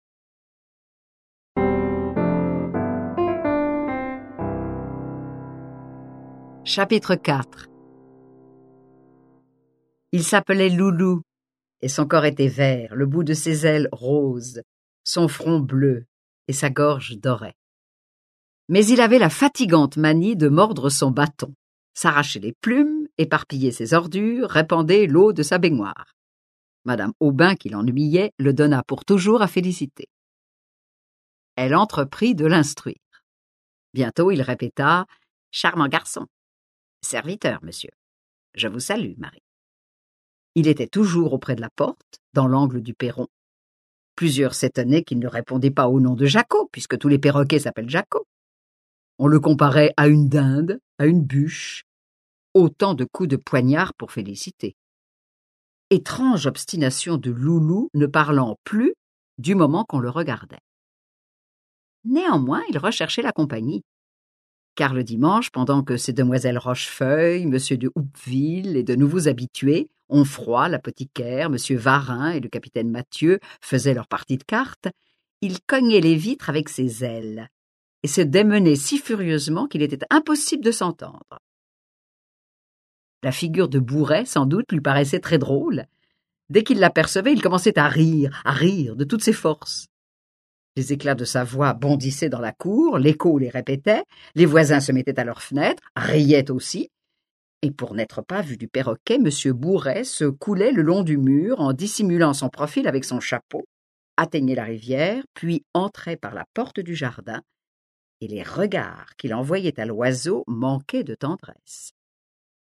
Click for an excerpt - Un cœur simple de Gustave Flaubert